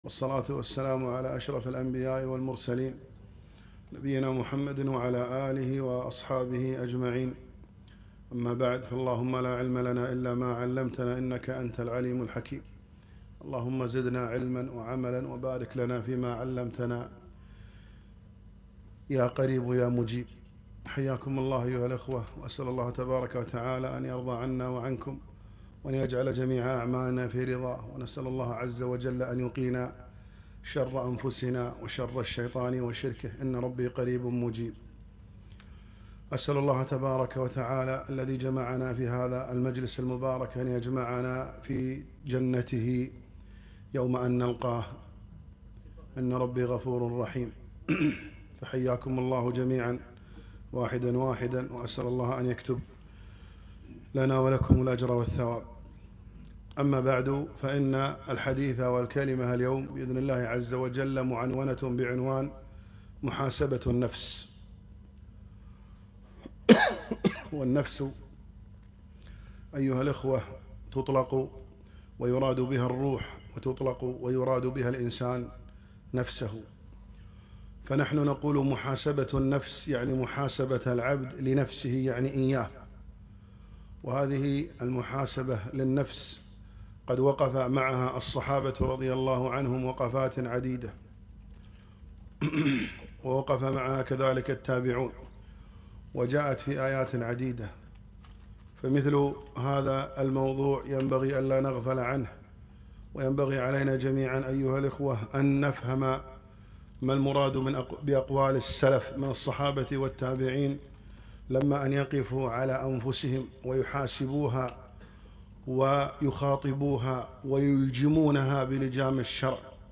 محاضرة - محاسبة النفس - دروس الكويت